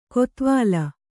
♪ kotvāla